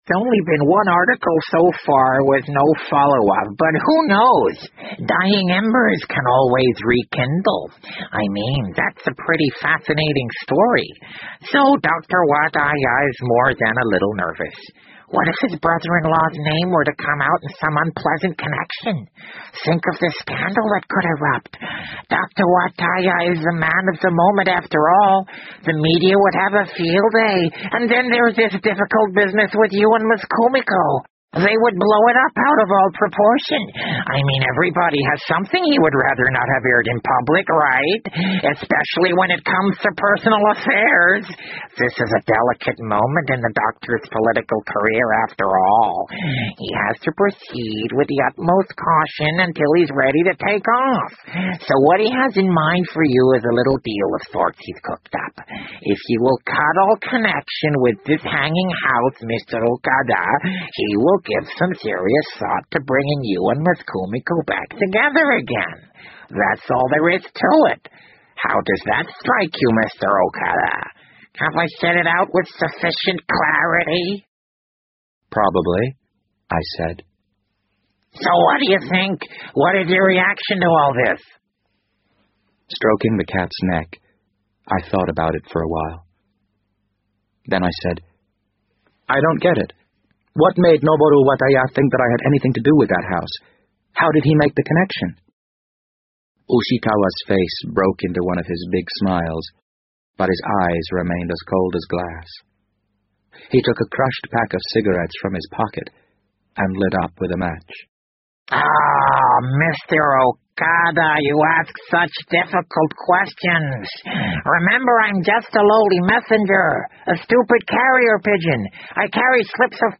BBC英文广播剧在线听 The Wind Up Bird 011 - 9 听力文件下载—在线英语听力室